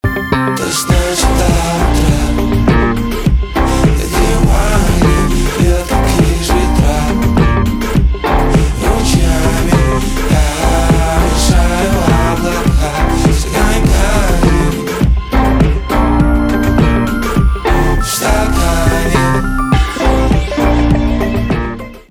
альтернатива
гитара , барабаны , кайфовые , чувственные